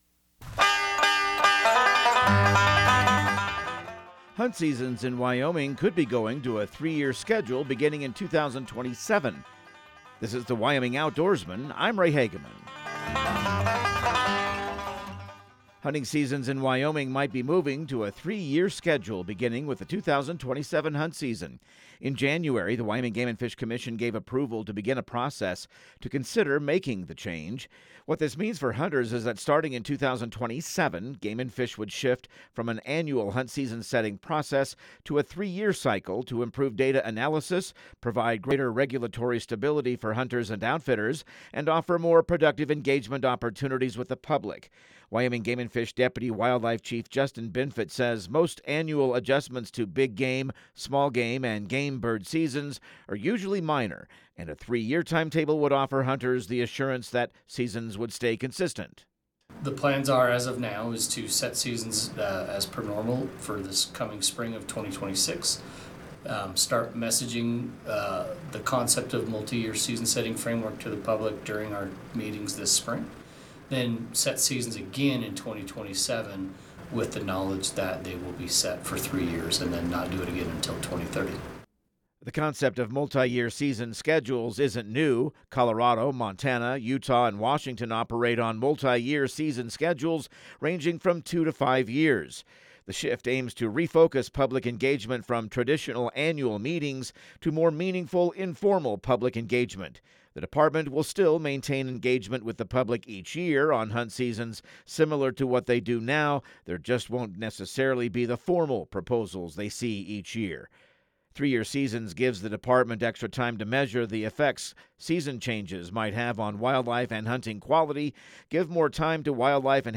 Radio news | Week of February 16